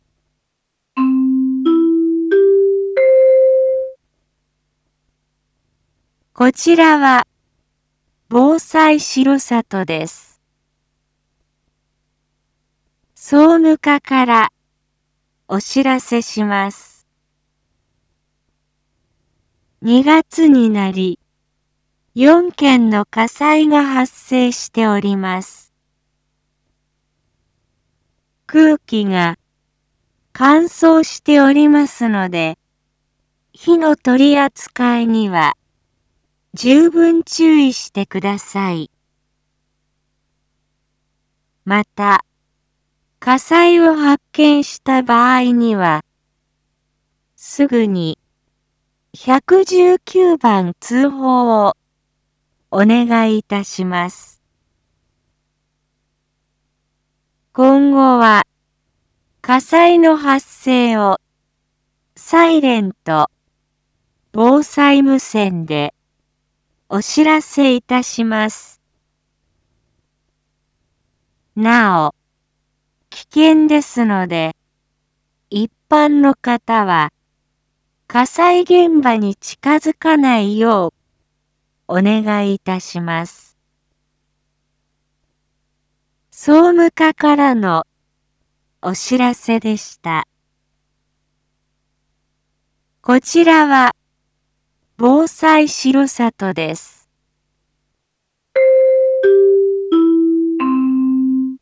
一般放送情報
Back Home 一般放送情報 音声放送 再生 一般放送情報 登録日時：2022-02-15 19:01:43 タイトル：火災注意喚起について インフォメーション：こちらは防災しろさとです。